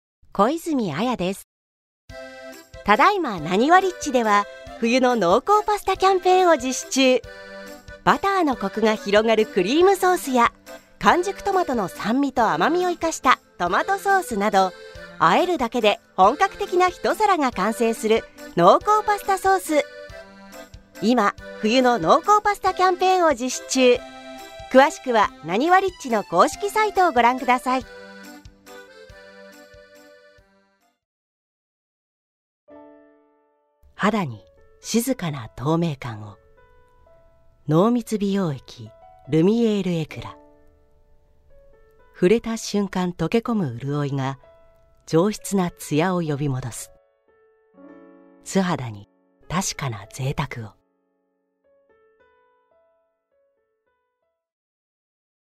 • 透明感ある正統派
• 音域：高～中音
• 声の特徴：：さわやか、落ち着き、正統派
• CM